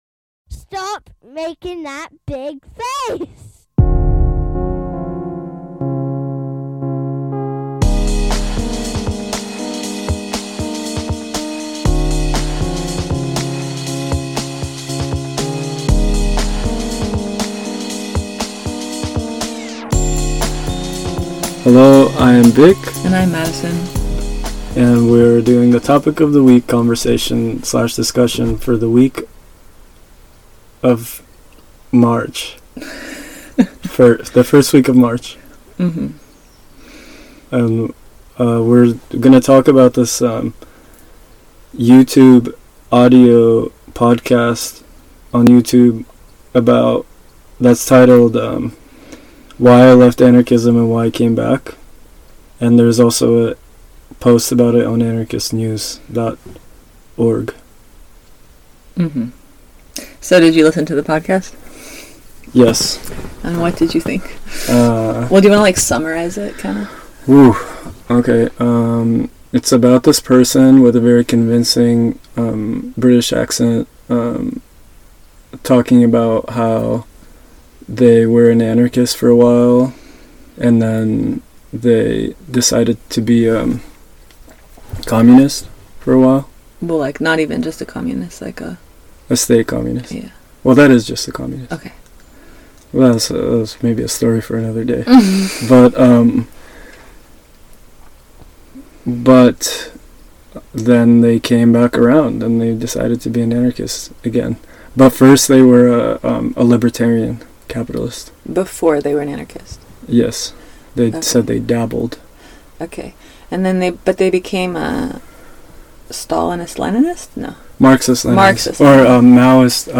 Topic of the Week / Article of the Week conversation: Why I left Anarchism, and then came back